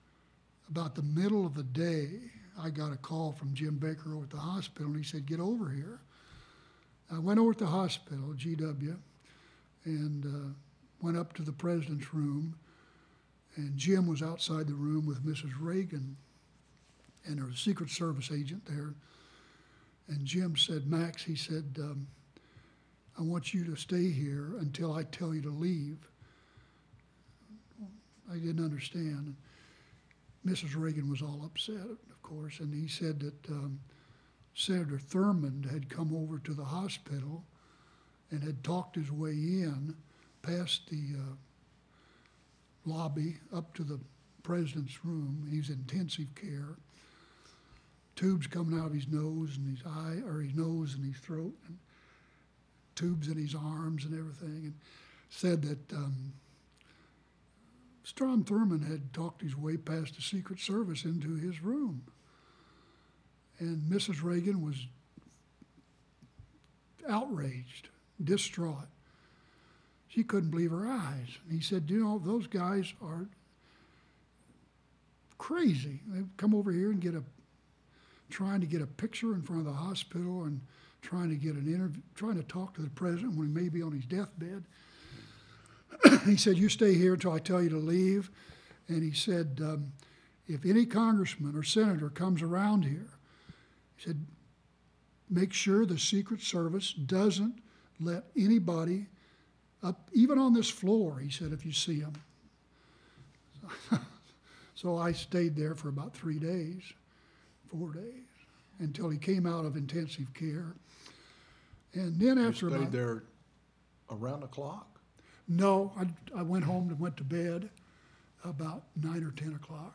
Date: October 24, 2002 Participants Max Friedersdorf Associated Resources Max Friedersdorf Oral History The Ronald Reagan Presidential Oral History Audio File Transcript